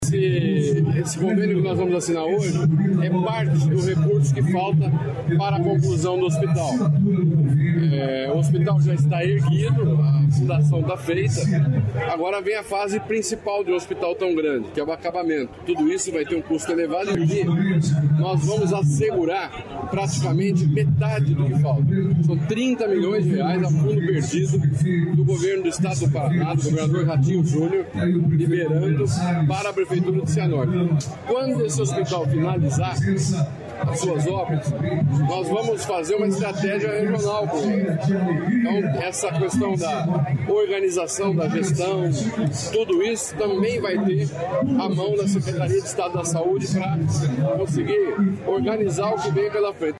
Sonora do secretário da Saúde, Beto Preto, sobre conclusão do hospital de Cianorte | Governo do Estado do Paraná